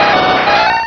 Cri de Galopa dans Pokémon Diamant et Perle.